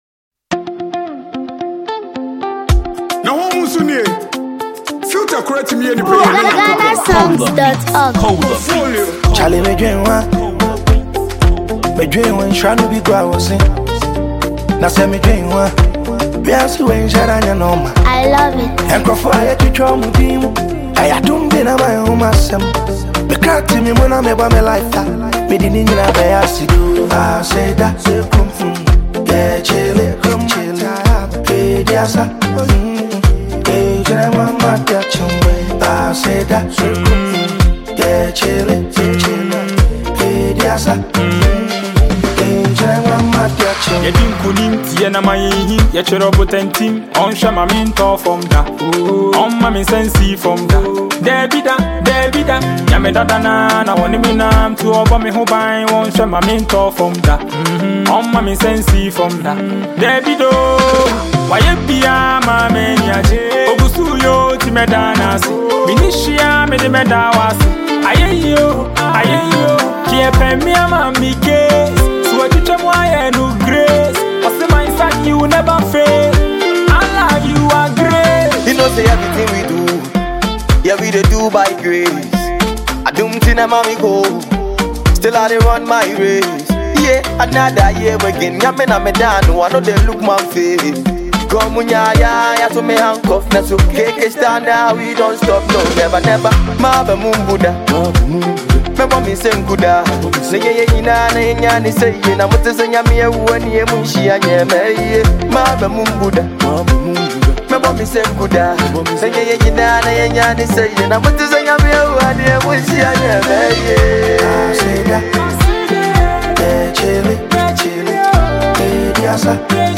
Ghanaian hip-hop